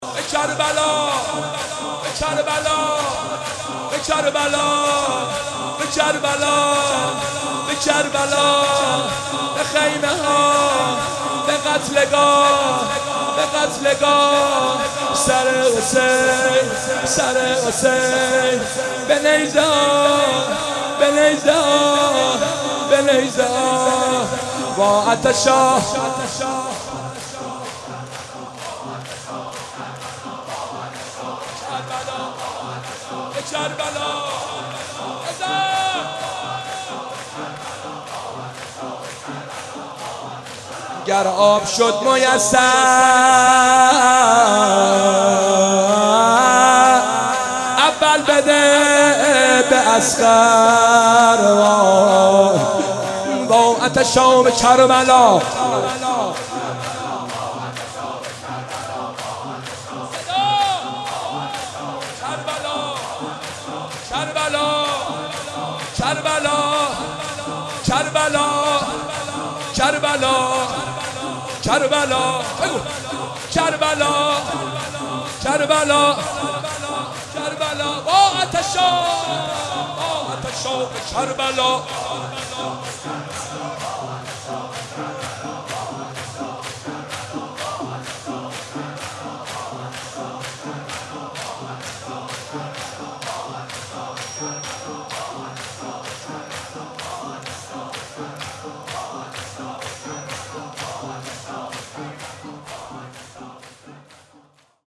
شور/ذکر